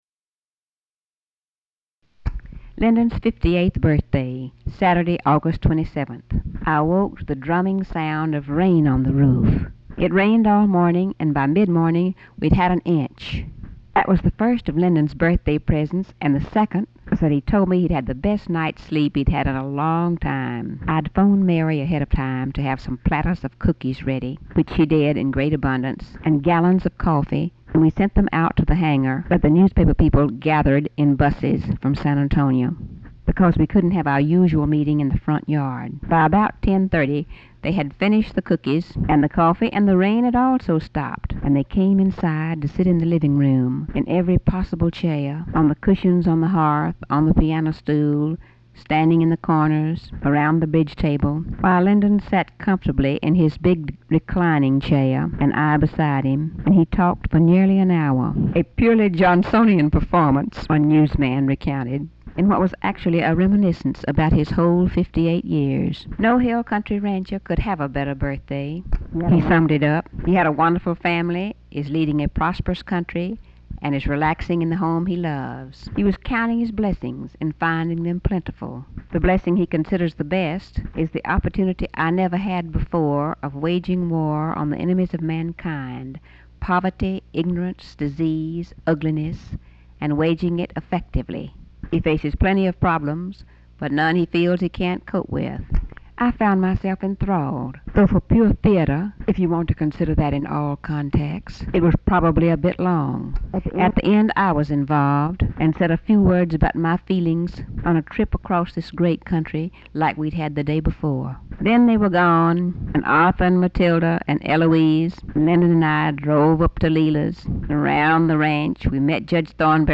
Personal diary
Audio tape; Paper